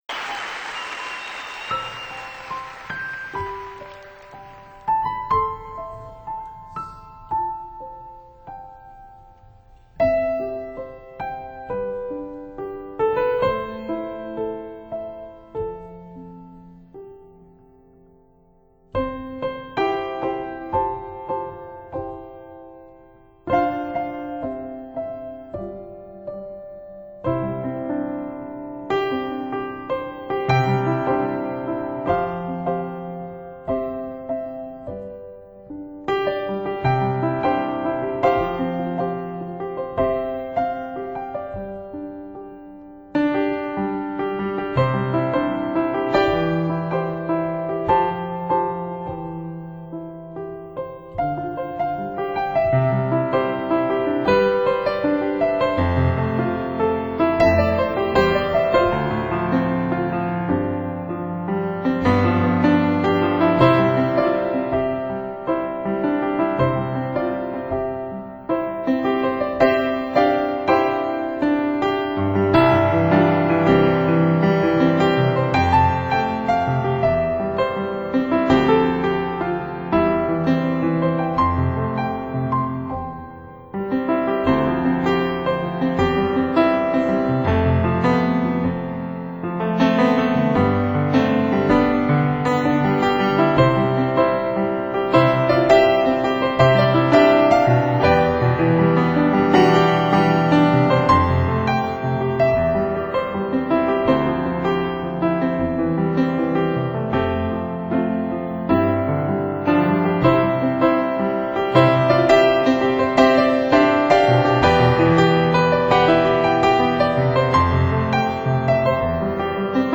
专辑主要是在2000年3月的盐湖城音乐会上现场录制的，再一次显示了他不一般的艺术天份。